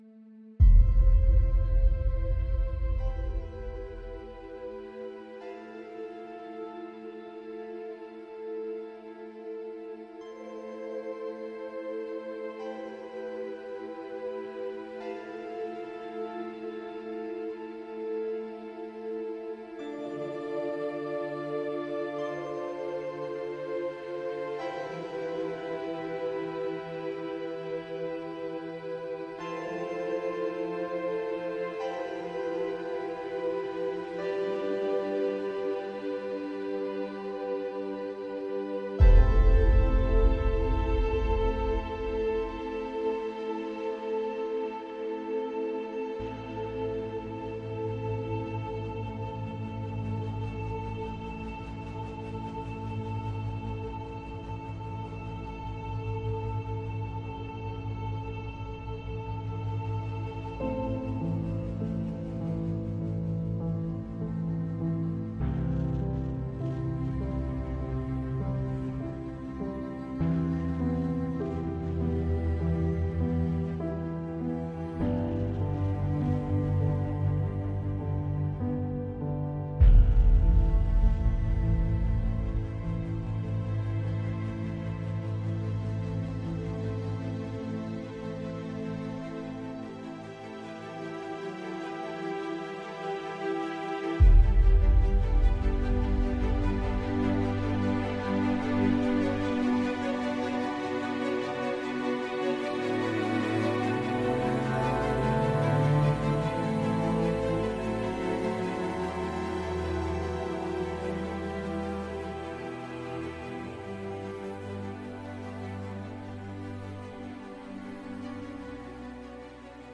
Thể loại: Nhạc nền video